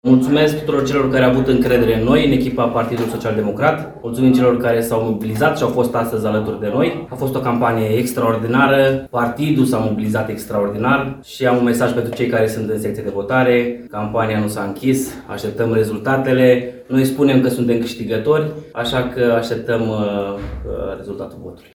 Între surprizele în urma scrutinului de ieri – Gheorghe Flutur (PNL) pierde președinția Consiliului Județean Suceava, arată datele parțiale publicate până acum. Cel considerat câștigător este candidatul PSD Gheorghe Șoldan – care a ținut un discurs după încheierea votului.